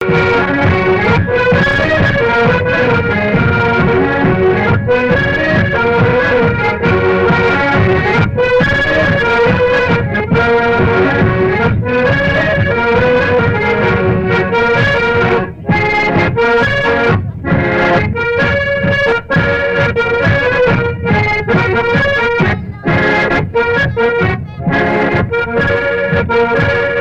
Beauvoir-sur-Mer
Chants brefs - A danser
polka des bébés ou badoise
Pièce musicale inédite